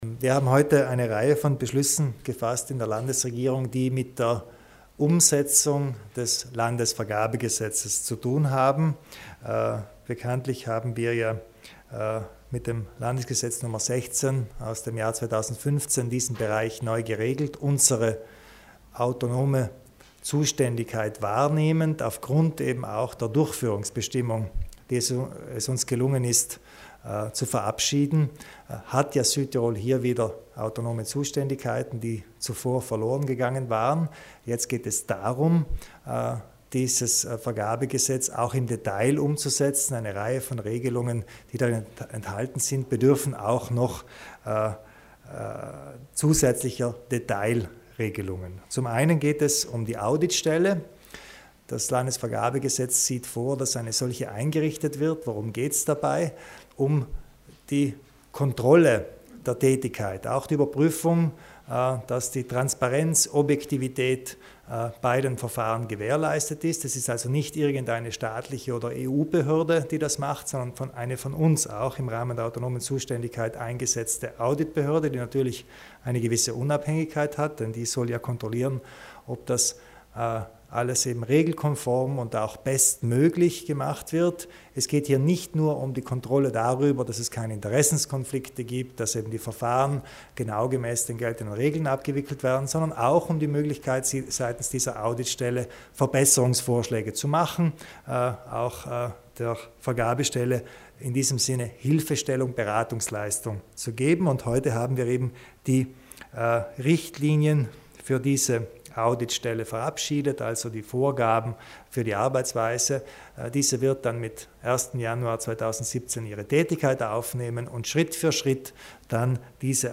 Landeshauptmann Kompatscher zu den Überprüfungen ei der Landesvergabeagentur